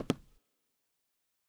ChessPieceSound.wav